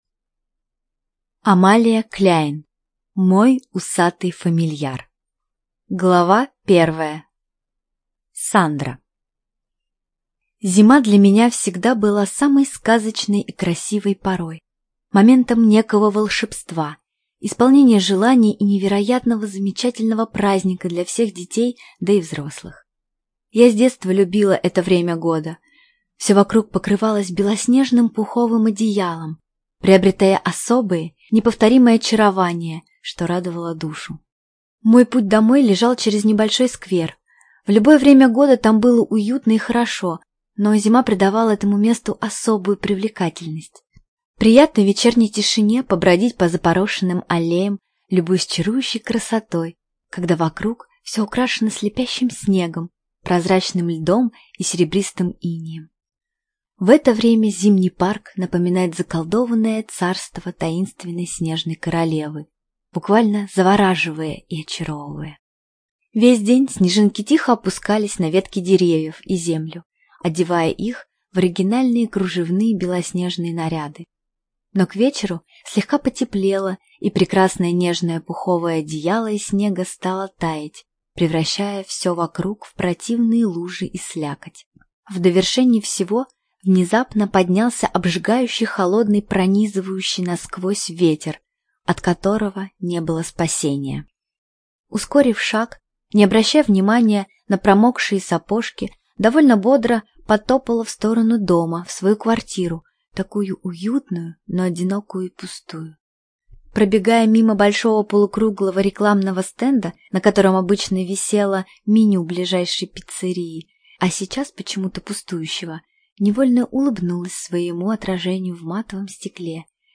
ЖанрЛюбовная проза, Фэнтези